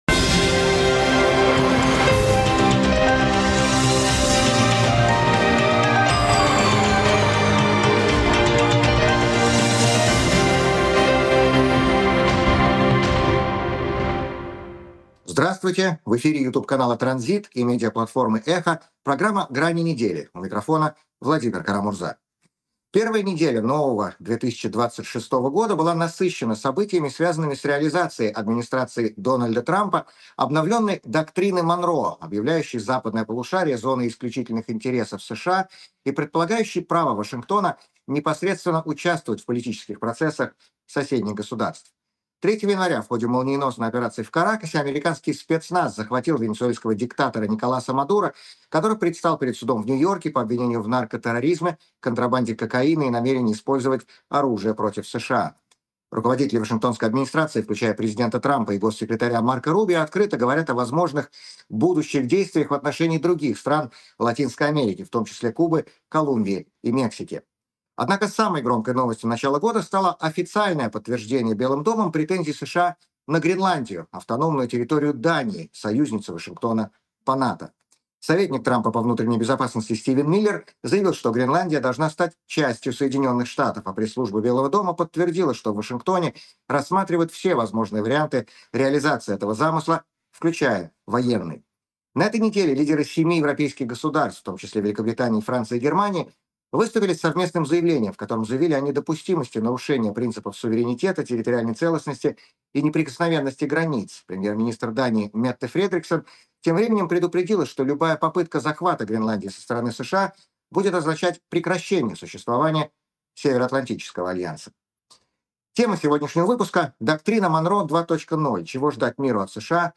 У микрофона Владимир Кара-Мурза.